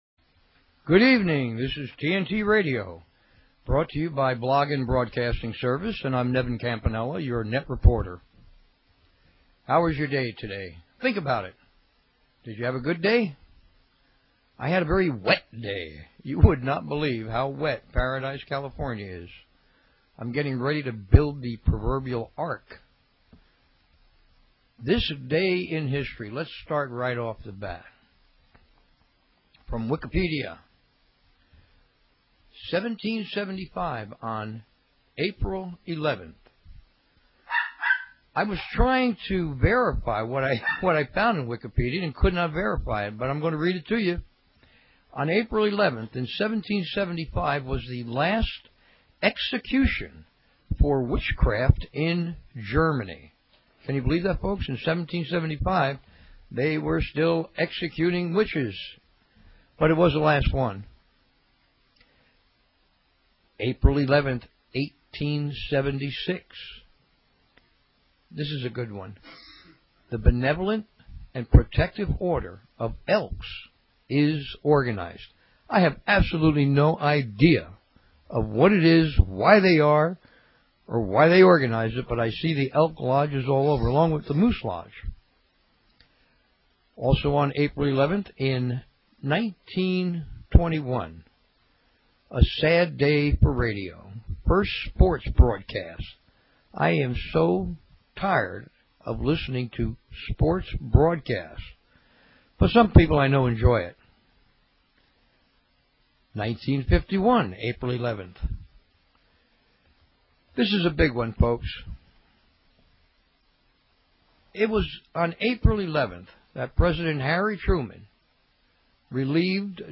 Talk Show Episode, Audio Podcast, TNT_Radio and Courtesy of BBS Radio on , show guests , about , categorized as